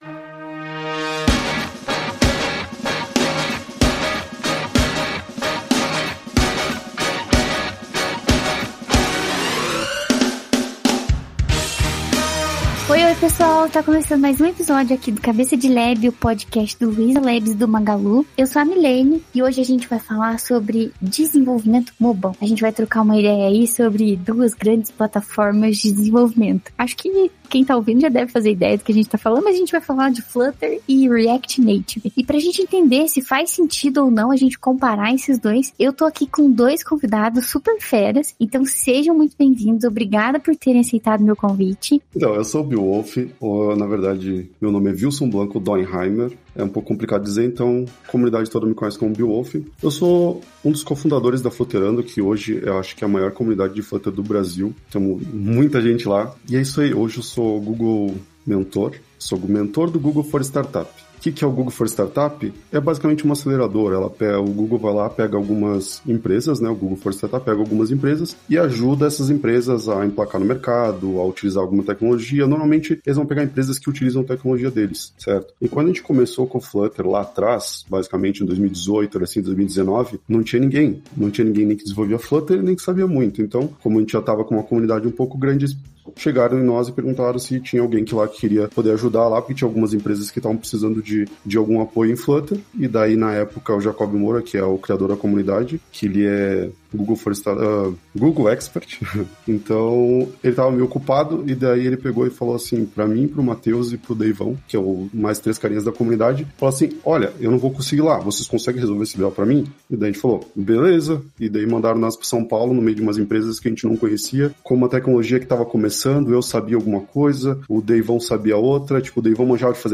E sobre as tecnologias usadas nesse tipo de desenvolvimento: Flutter e React Native? No episódio de hoje chamamos pessoas que são especialistas nessas duas tecnologias para batermos um papo e apresentarmos diferenças, vantagens e curiosidades entre essas grandes tecnologias usadas no mercado.